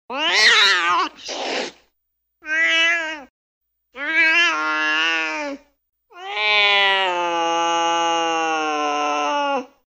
Звуки шипения кошки
На этой странице собрана коллекция звуков шипения кошек в разных ситуациях: от лёгкого недовольства до агрессивного предупреждения.
Все записи натуральные, с хорошим качеством звучания.
Кошка злобно рычит